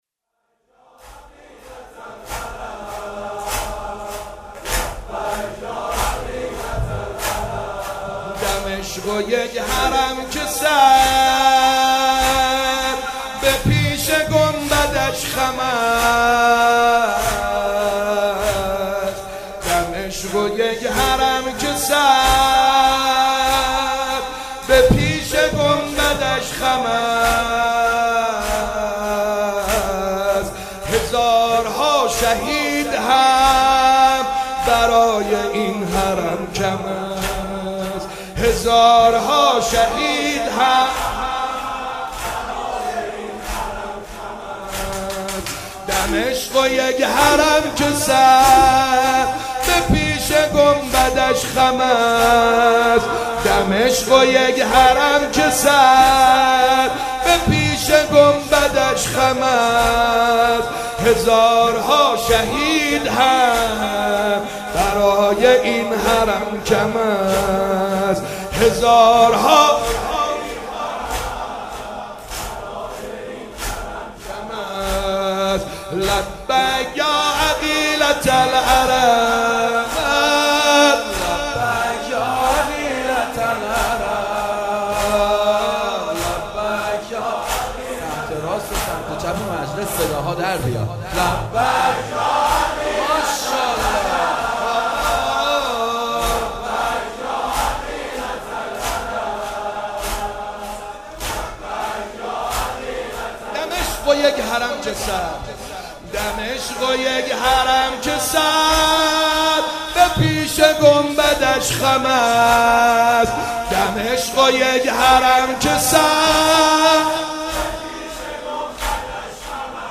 شهادت حضرت معصومه(س)/هیئت مکتب الزهرا(س)